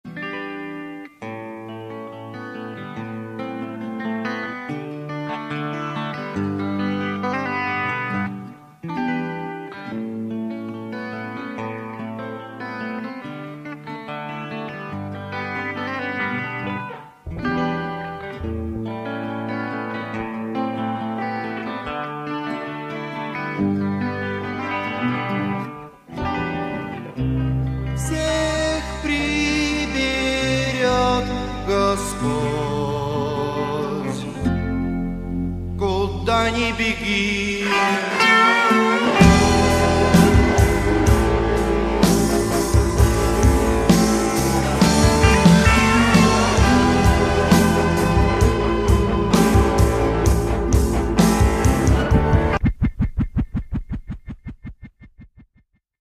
я поучаствовал на сессии как гитарист